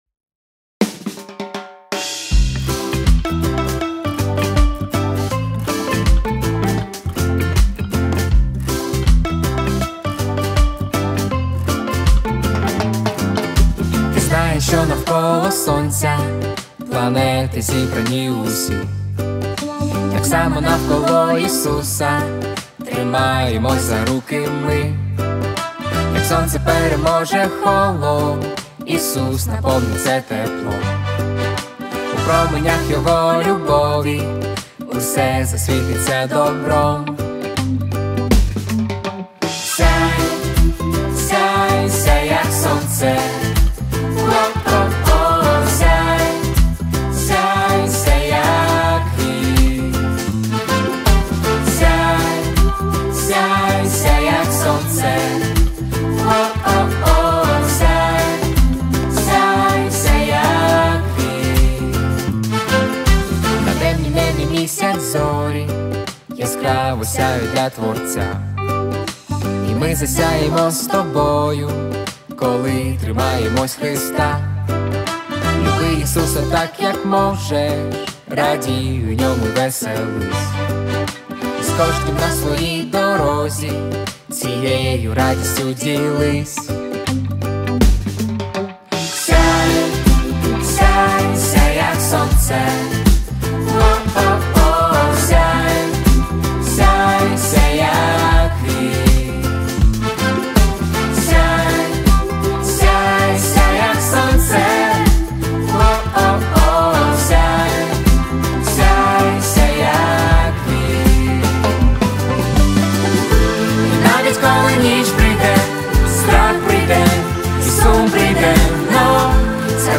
3163 просмотра 1204 прослушивания 404 скачивания BPM: 158